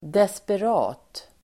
Uttal: [desper'a:t]